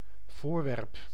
Ääntäminen
Synonyymit article thing target item aim purpose sucker expostulate Ääntäminen US : IPA : [ˈɒbdʒekt] US : IPA : [əbˈdʒekt] Tuntematon aksentti: IPA : /ˈɒb.dʒɛkt/ IPA : /ˈɑb.dʒɛkt/ IPA : /əbˈdʒɛkt/ Lyhenteet ja supistumat (kielioppi) obj